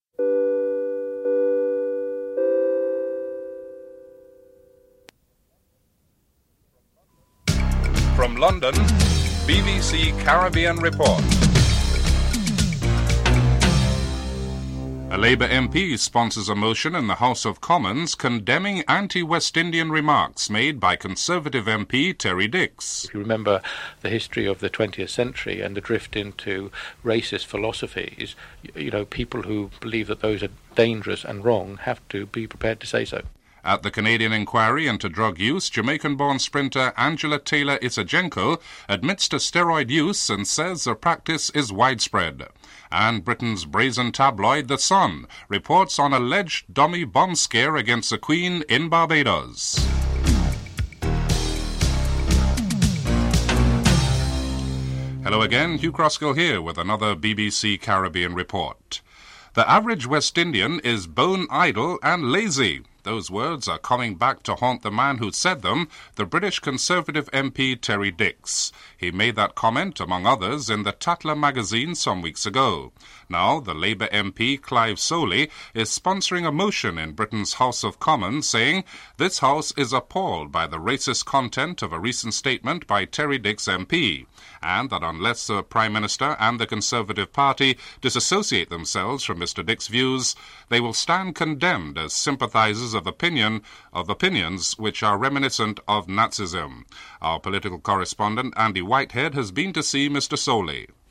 2. Interview with Labour MP, Clive Solely on his sponsoring of a motion in the House of Commons on a racist statement made by Conservative MP, Terry Dicks (00:54-04:10)
5. Security row over dummy bombs at the Queen's visit to Barbados. Interview with Orville Durrant, Barbados' Commissioner of Police (08:09-10:07)